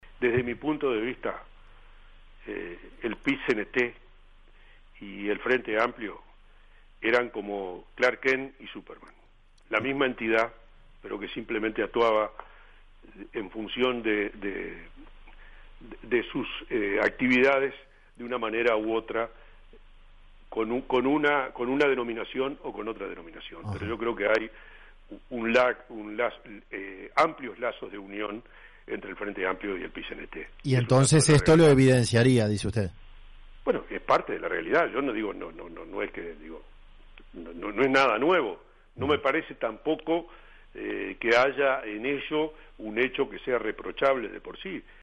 Entrevistado en 970 Universal, el diputado del Partido Independiente, Iván Posada realizó esa comparación